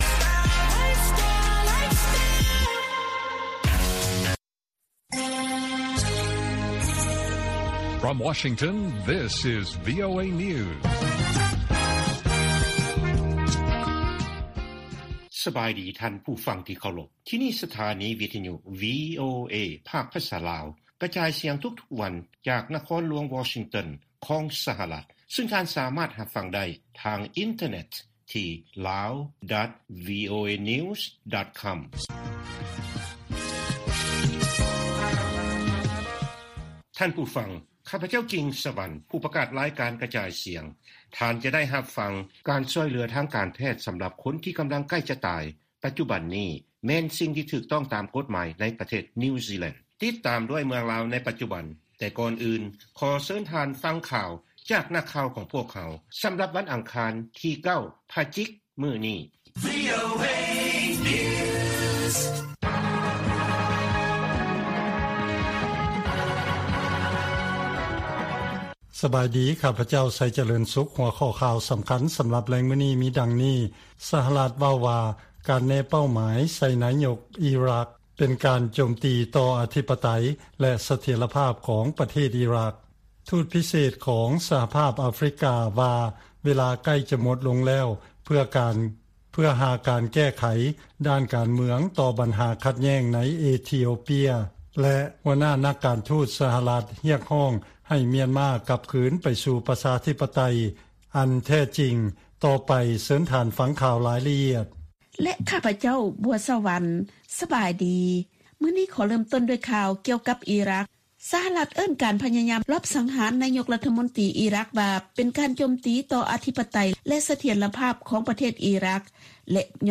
ລາຍການກະຈາຍສຽງຂອງວີໂອເອ ລາວ: ປະທານາທິບໍດີໄບເດັນ ປະເຊີນກັບການຮຽກຮ້ອງ ໃຫ້ຜ່ອນຜັນການລົງໂທດ ຕໍ່ເກົາຫຼີເໜືອ